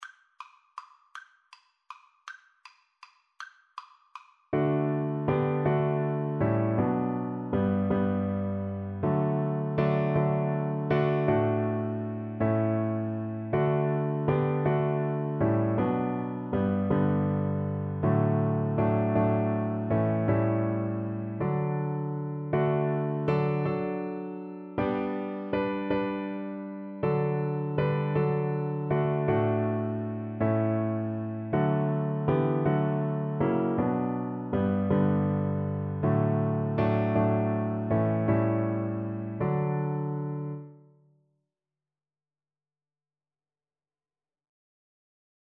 6/4 (View more 6/4 Music)